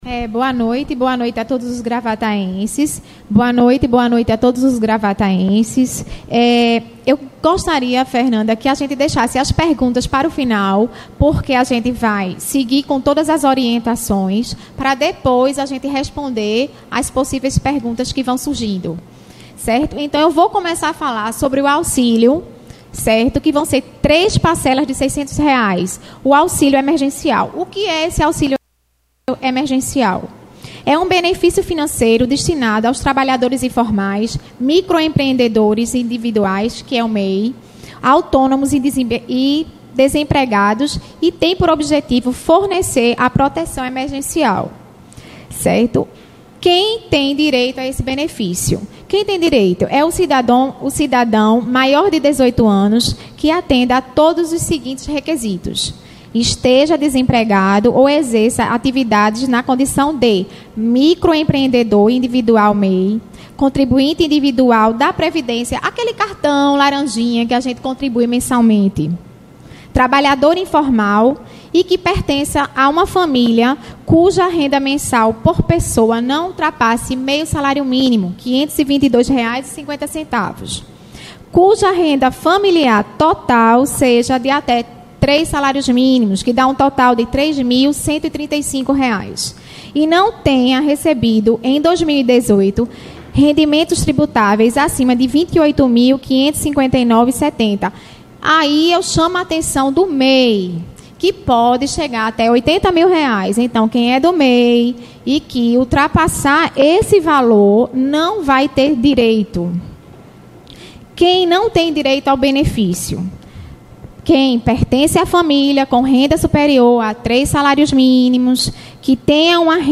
A Secretária de Assistência Social de Gravatá, Ana Lourdes, participou na noite desta quarta-feira (8) de coletiva de imprensa para falar sobre o auxílio emergencial do coronavírus, anunciado pelo Governo Federal, cujo um dos beneficiários são àqueles que possuem CadÚnico.
ANINHA-ENTREVISTA.mp3